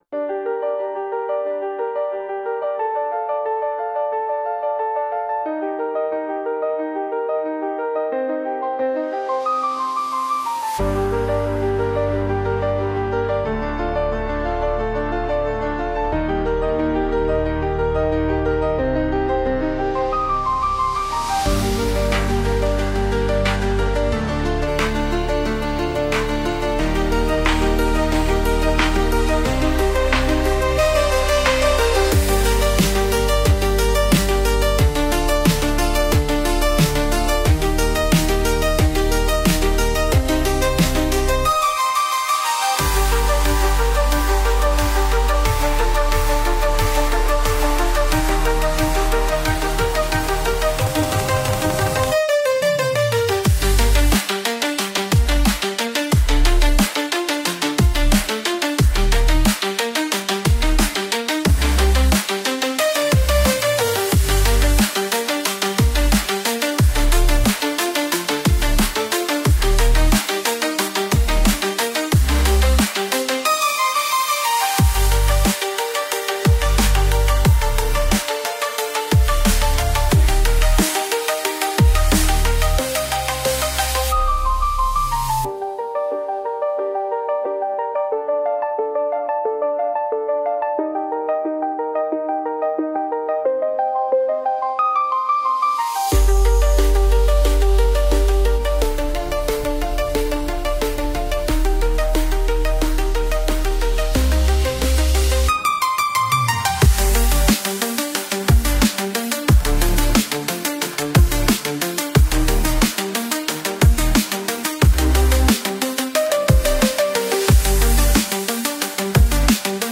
Instrumentalstück